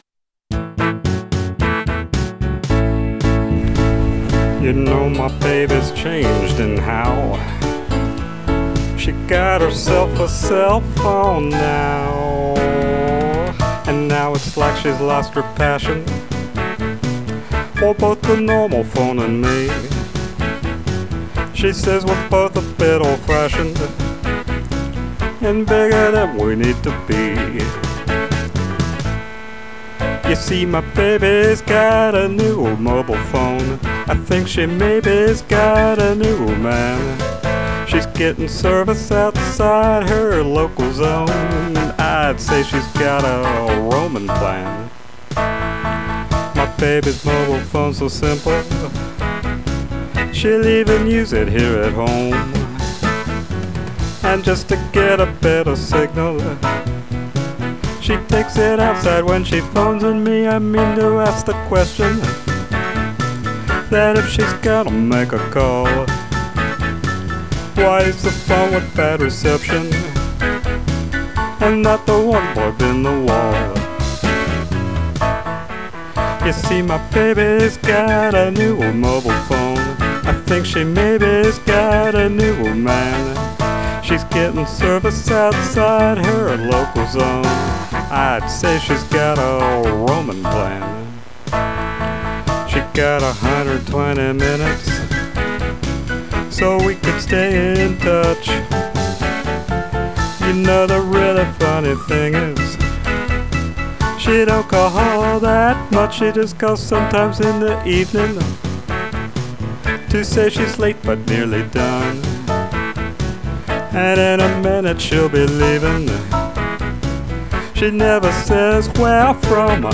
blues or country rock, male voice